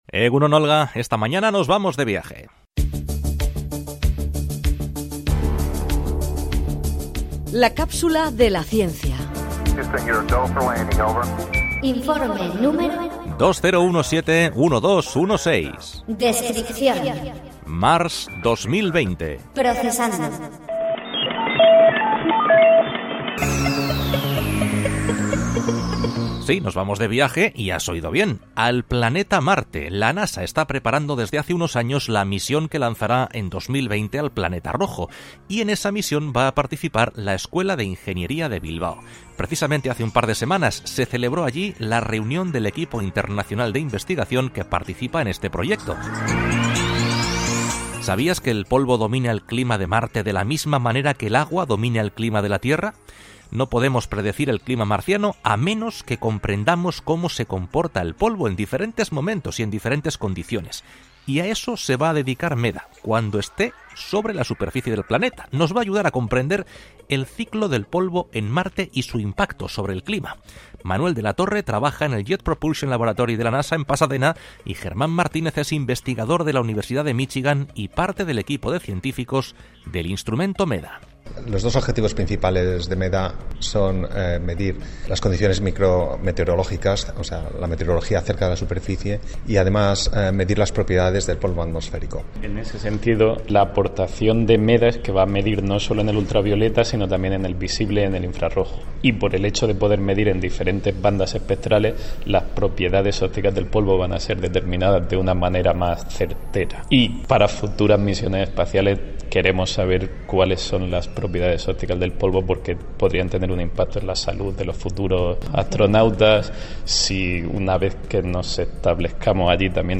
Son los científicos elegidos por la NASA para analizar el ciclo del polvo en Marte y cómo afecta a su clima.